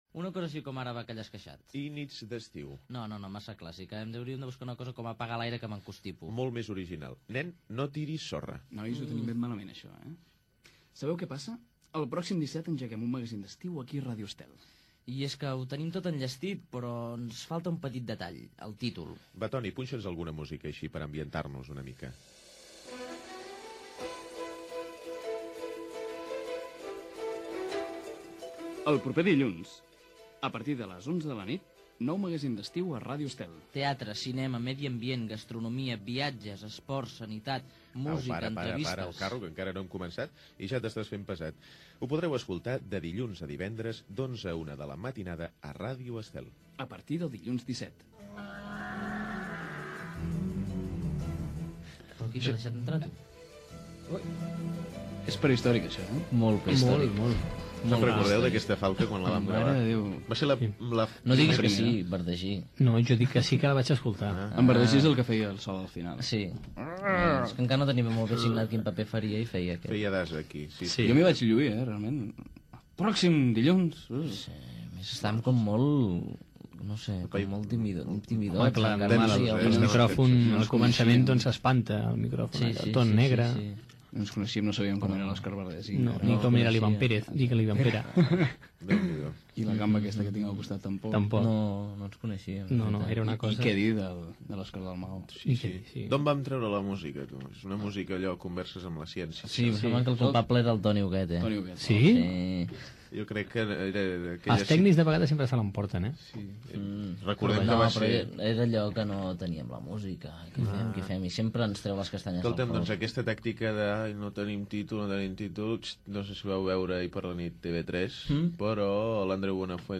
Gènere radiofònic Entreteniment
Banda FM